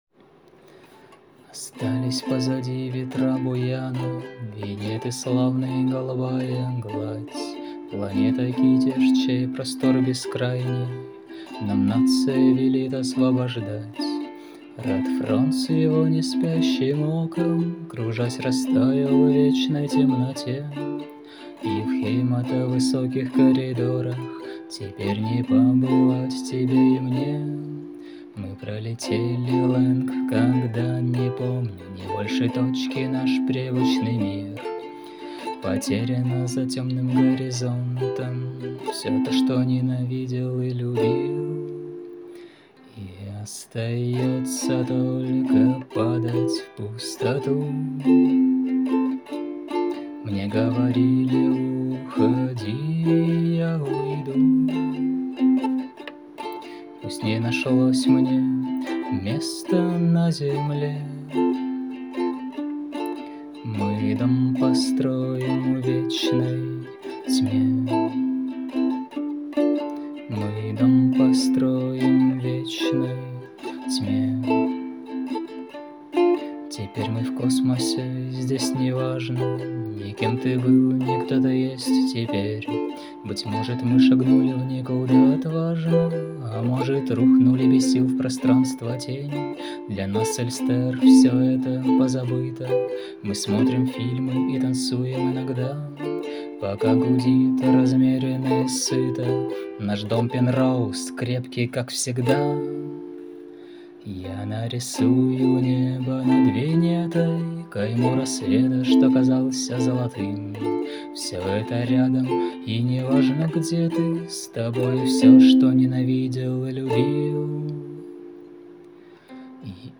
Голос хорошо звучит, но сама песня невыразительная.